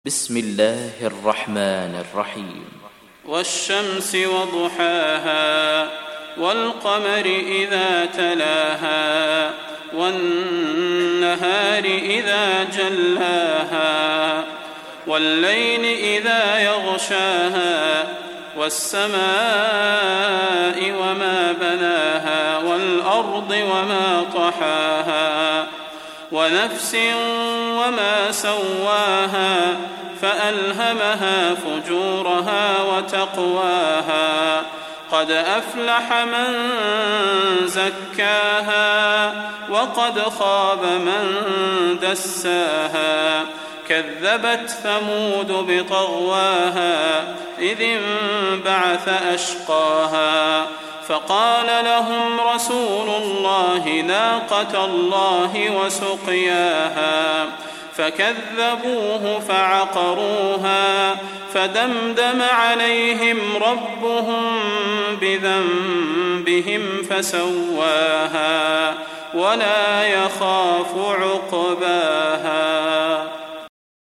تحميل سورة الشمس mp3 بصوت صلاح البدير برواية حفص عن عاصم, تحميل استماع القرآن الكريم على الجوال mp3 كاملا بروابط مباشرة وسريعة